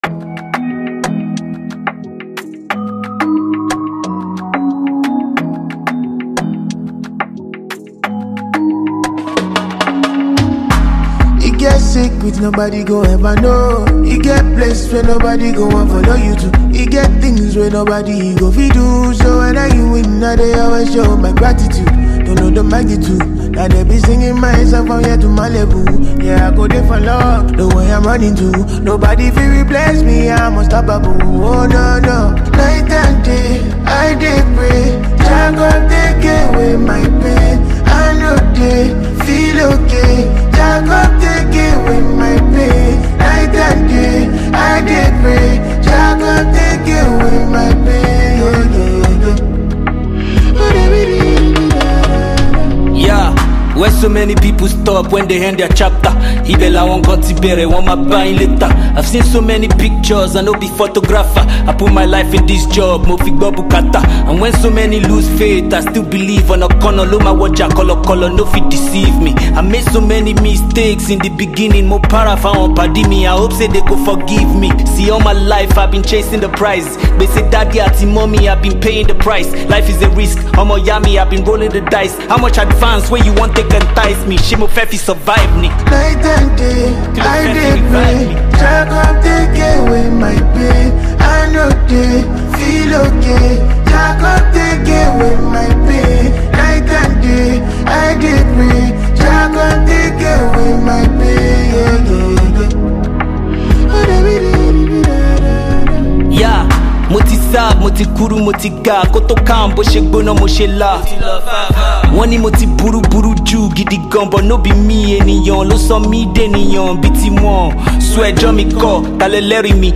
is a mellow Afrobeats record